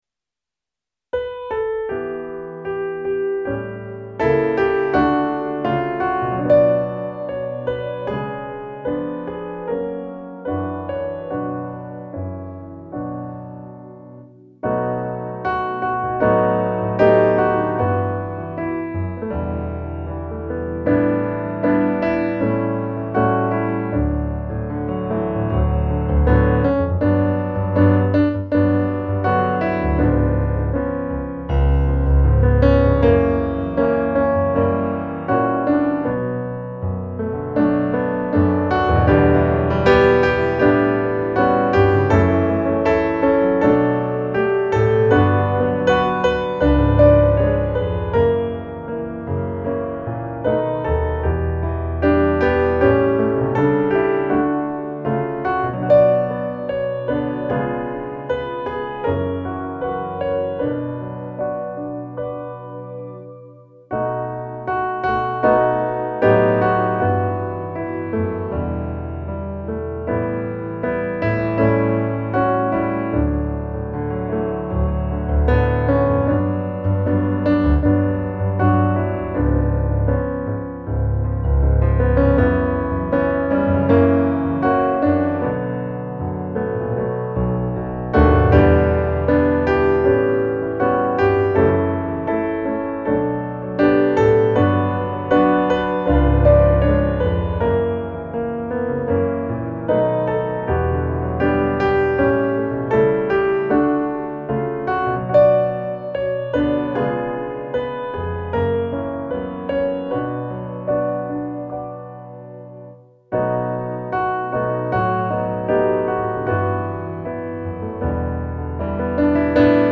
Musikbakgrund Psalm